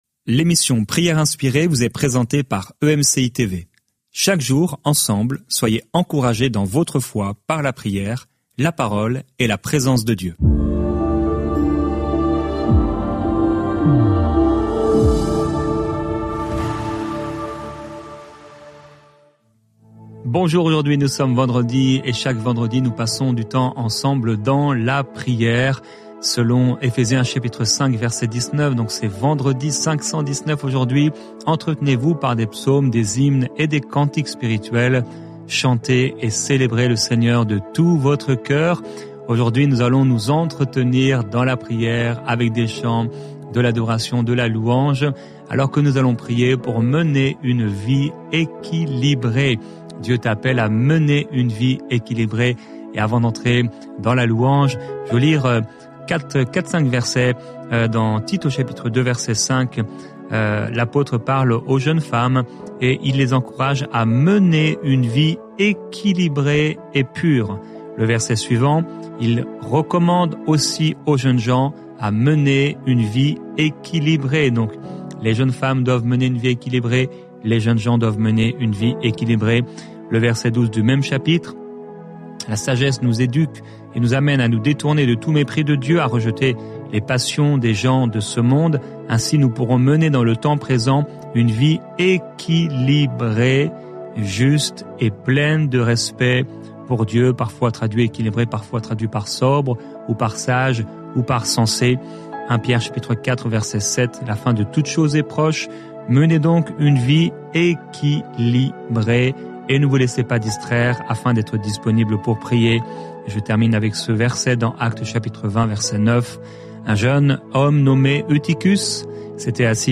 Au programme, une pensée du jour, un temps de louange, l'encouragement du jour et un temps de prière et de déclaration prop